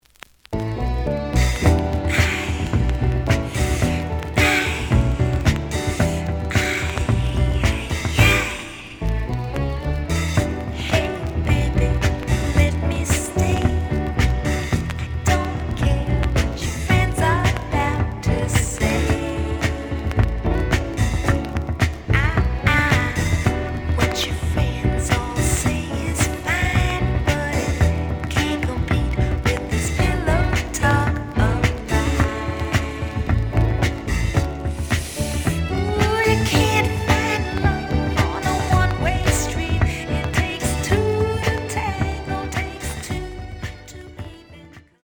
The audio sample is recorded from the actual item.
●Genre: Soul, 70's Soul
Some click noise on A side due to scratches.